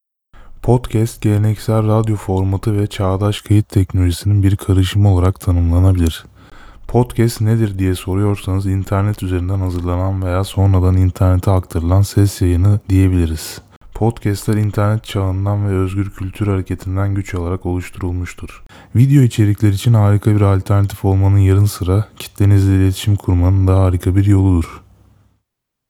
Shure Sm7b Problems
The final sample, #6 seemed to have more bass to the voice, probably due to proximity boost. Sample #5 sounds slightly more natural to me.
The volume was roughly equalized between the samples, then I split each section in parts and alternated the sections. The samples go 5 - 6 - 5 - 6 - 5 - 6. Noise level sounds similar to me, the difference is in the level of bass in the voice.